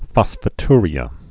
(fŏsfə-trē-ə, -tyr-)